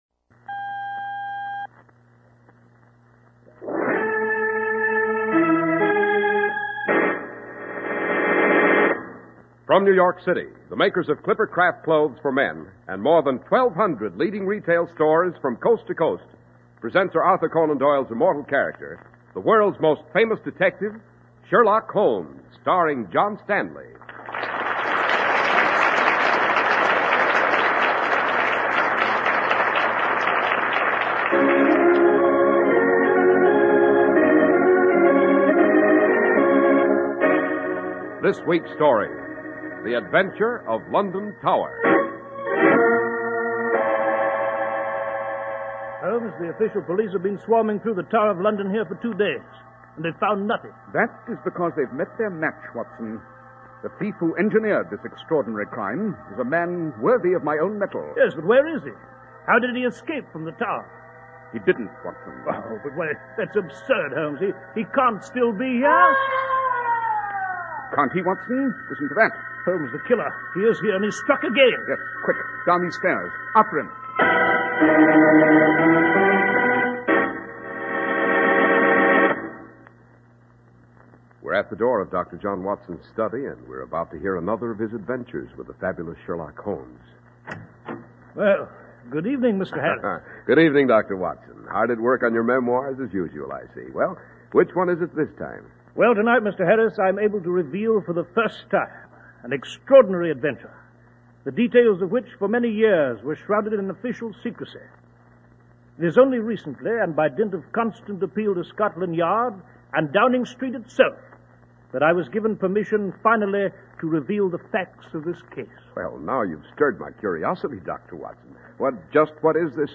Radio Show Drama with Sherlock Holmes - The London Tower 1948